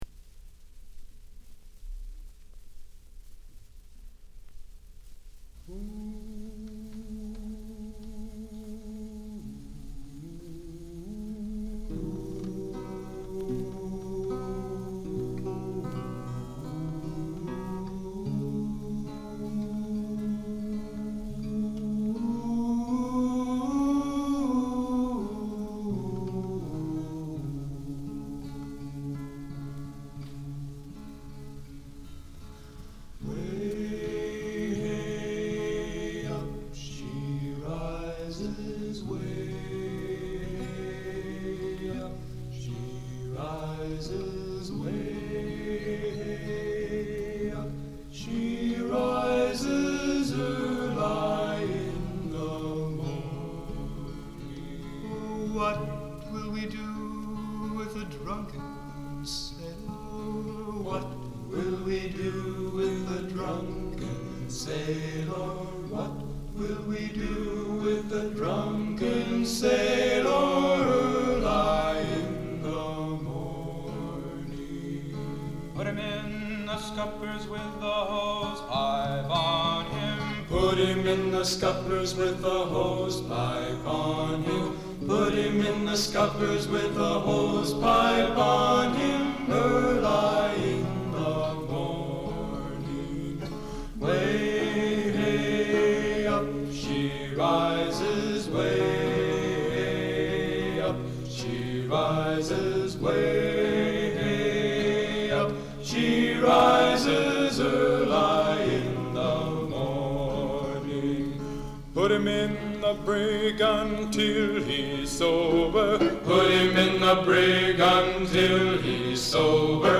Remember the folk songs we listened to and sang during our years at Whitman?